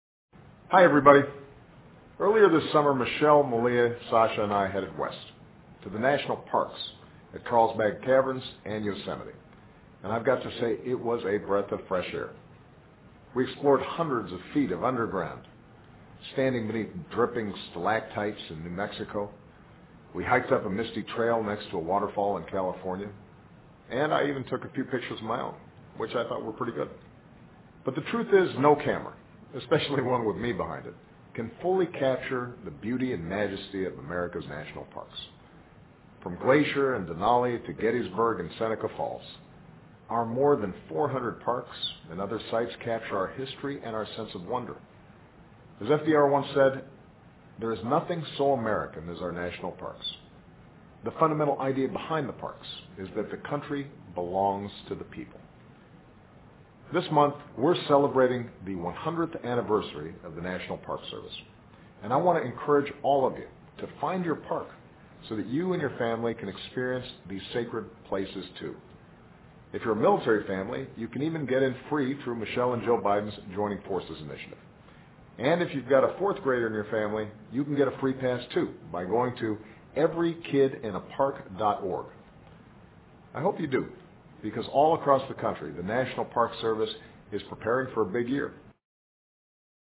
奥巴马每周电视讲话：总统庆贺国家公园管理局设立100 周年（01） 听力文件下载—在线英语听力室